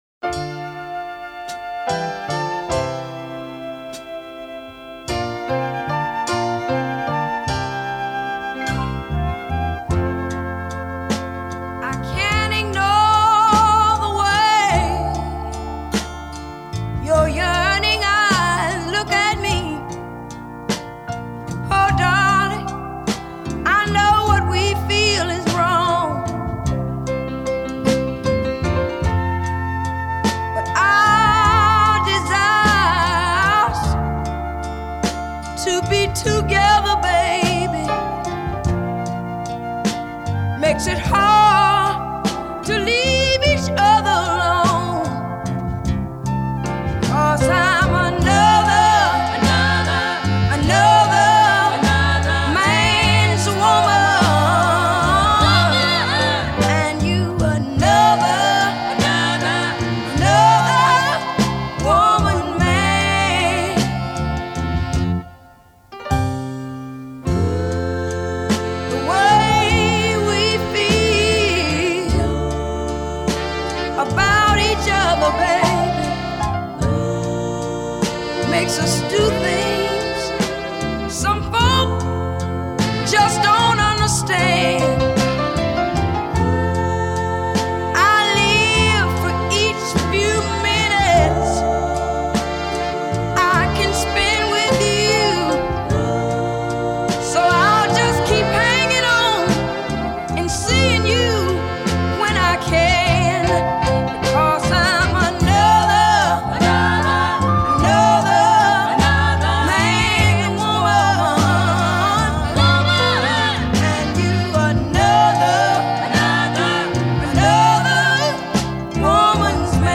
Tag: southern soul